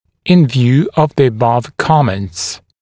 [ɪn vjuː əv ðə ə’bʌv ‘kɔments][ин вйу: ов зэ э’бав ‘комэнтс]с учетом вышесказанного; имея в виду вышеприведенные комментарии